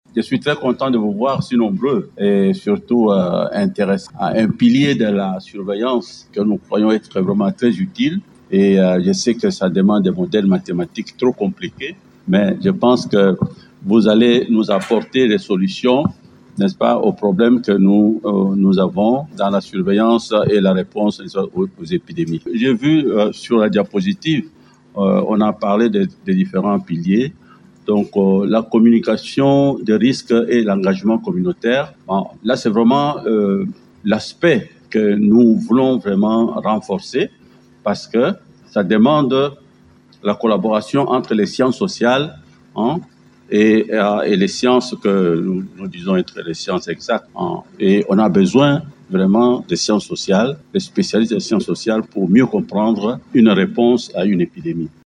Un master de modélisation appliquée en santé globale (MASG) a été lancé, lundi 5 mai, à Kinshasa, au cours d’une cérémonie scientifique organisée à l’amphithéâtre de l’Institut national de recherche biomédicale (INRB).
Le directeur général de cet institut, Dr Jean-Jacques Muyembe, a exhorté les étudiants à se démarquer afin de contribuer efficacement à la surveillance et à la réponse aux épidémies.
Vous pouvez écouter Dr Jean-Jacques Muyembe :